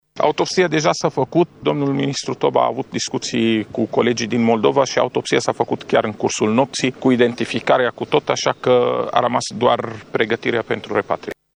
La faţa locului s-a aflat şi secretarul de stat în Ministerul de Interne, Raed Arafat, însoţit de specialişti în aviaţie şi de procurori criminalişti. El a declarat că repatrierea corpurilor celor trei salvatori români va fi făcută astăzi: